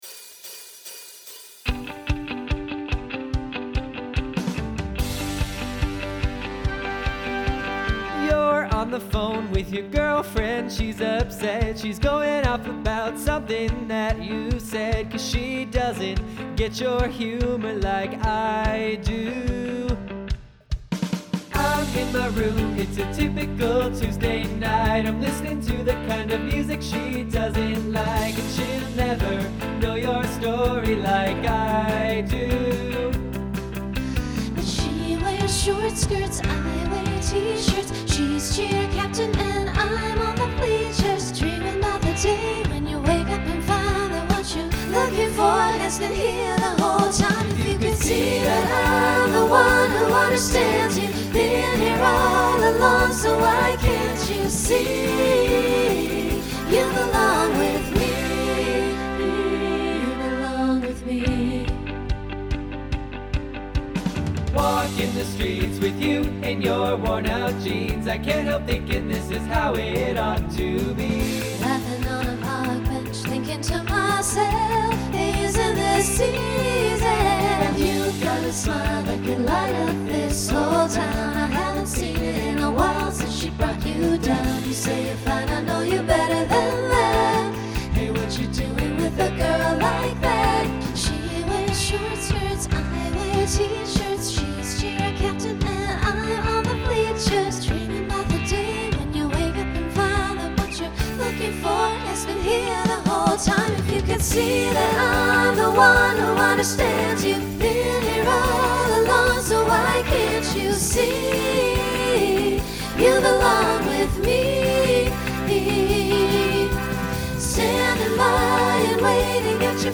SATB Instrumental combo Genre Country
Mid-tempo